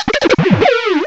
cry_not_bibarel.aif